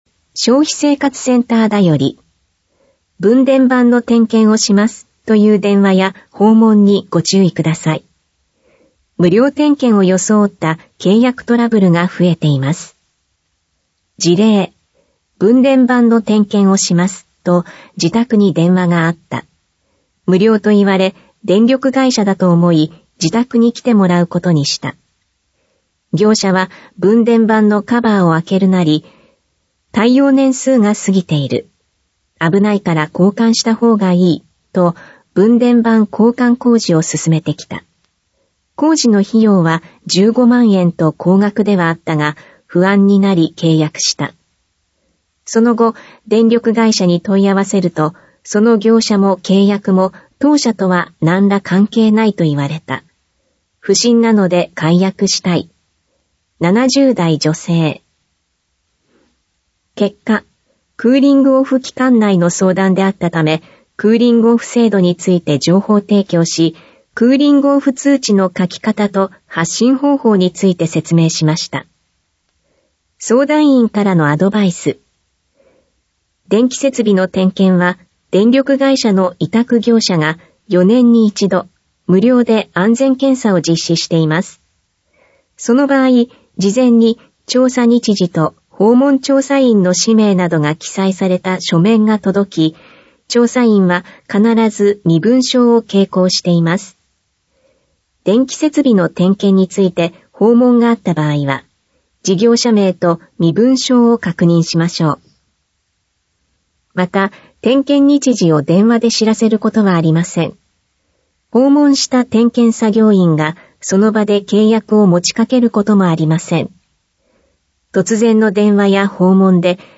市報こだいら2026年3月5日号音声版